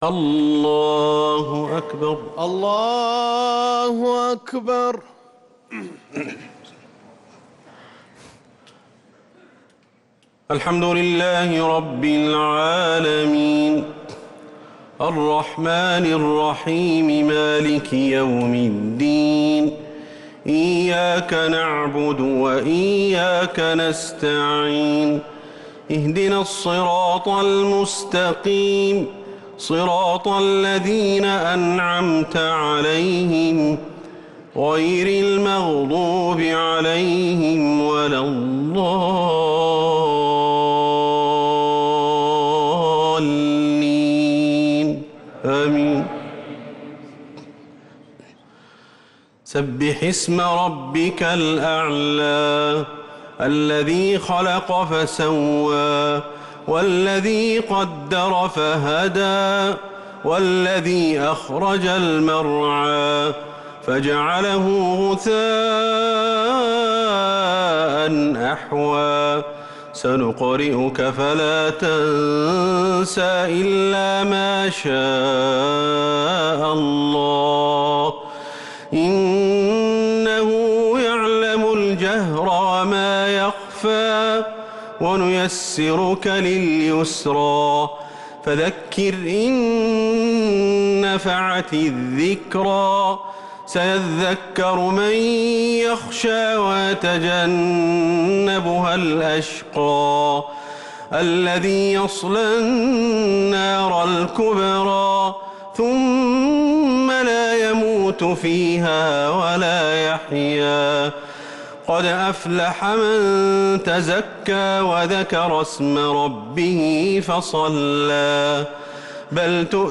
الشفع والوتر ليلة 24 رمضان 1446هـ | Witr 24th night Ramadan 1446H > تراويح الحرم النبوي عام 1446 🕌 > التراويح - تلاوات الحرمين